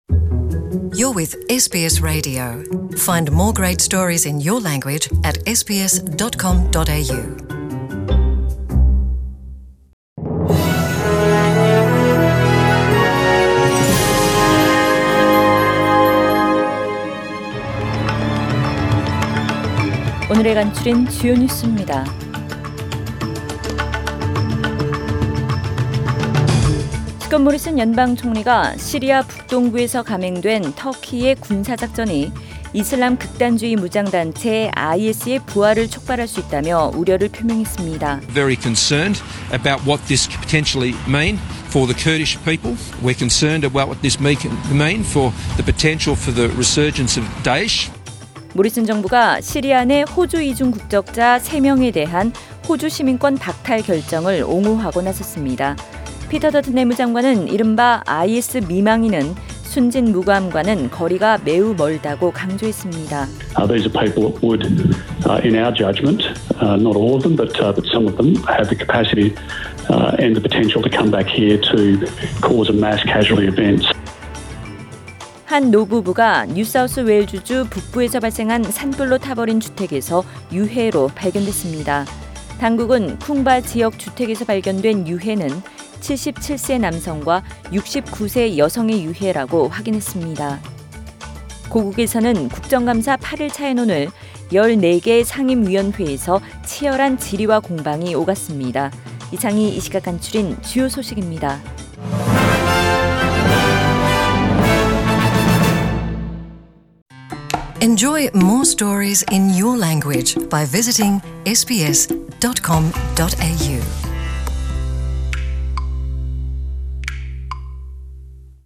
SBS 한국어 뉴스 간추린 주요 소식 – 10월 10일 목요일